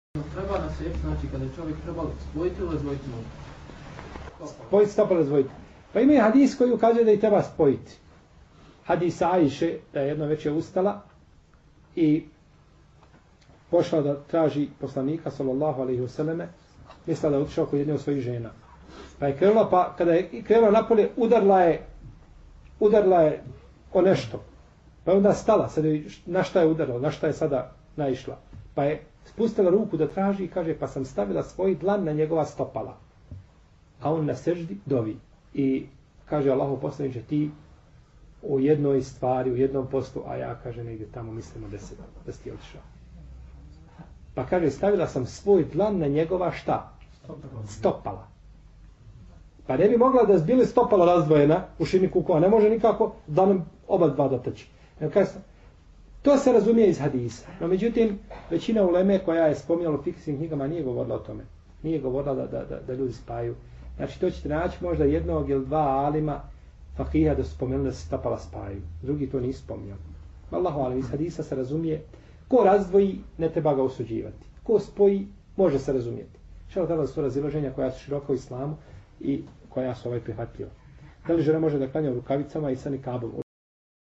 odgovor je na 3,14 min predavanja na linku ispod